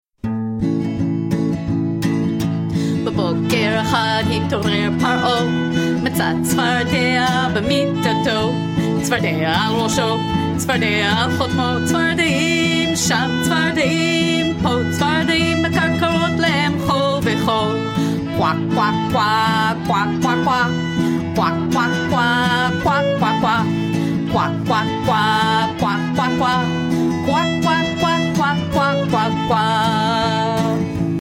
vocals and violin